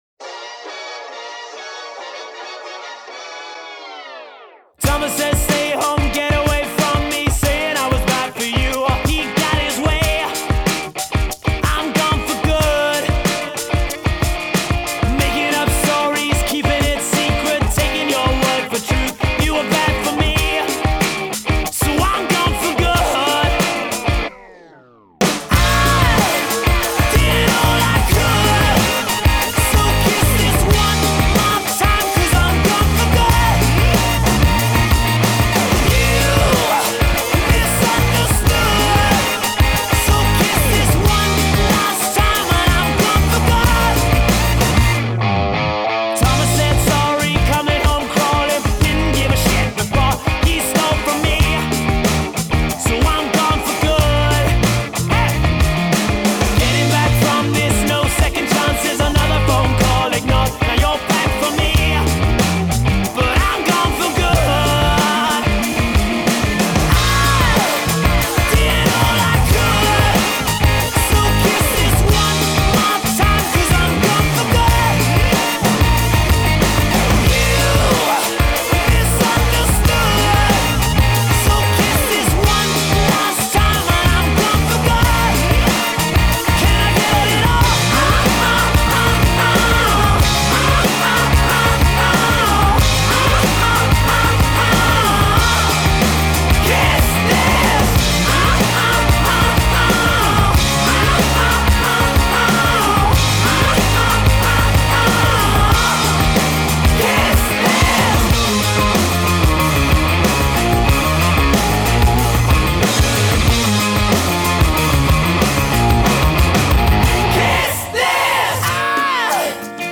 Genre: Rock, Indie Rock